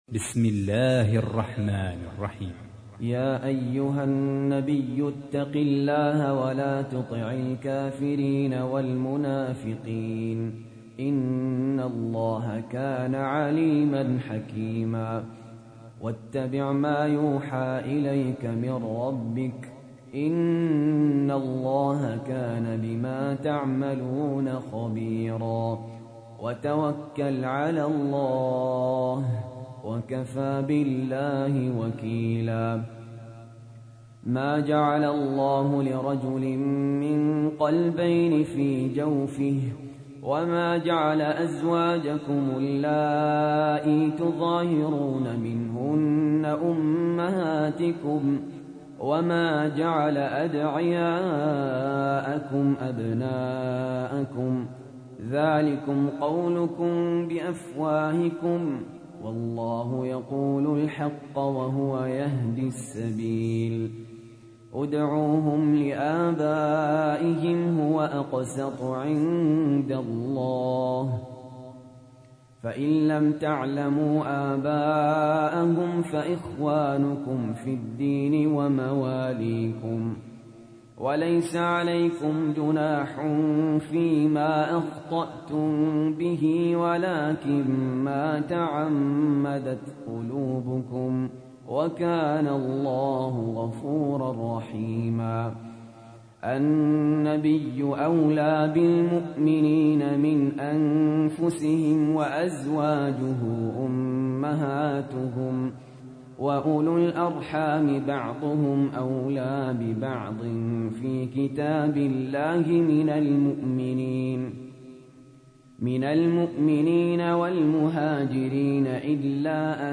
تحميل : 33. سورة الأحزاب / القارئ سهل ياسين / القرآن الكريم / موقع يا حسين